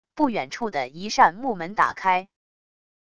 不远处的一扇木门打开wav音频